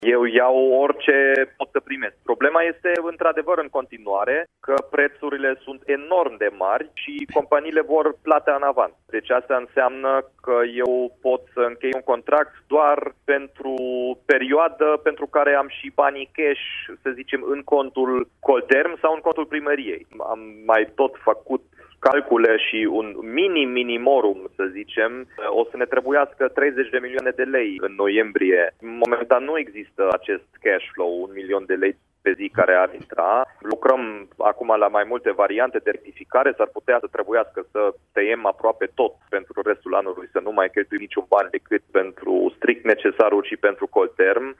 Dominic Fritz a mai precizat la Radio Timișoara că, în condițiile actuale, este deschis la orice ofertă, dar firmele vor banii în avans, și doar pentru noiembrie ar fi necesar un milion de lei pe zi.